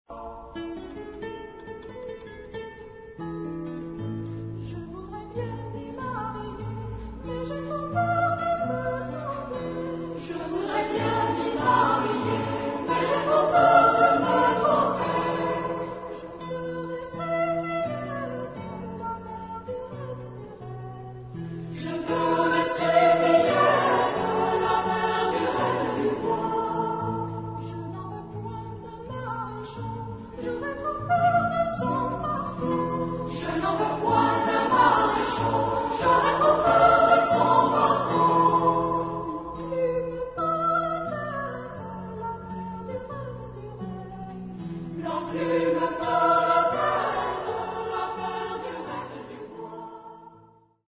Genre-Style-Form: Popular ; Children ; Secular
Type of Choir: SSA  (3 women voices )
Soloist(s): Soprano (1)  (1 soloist(s))
Tonality: A major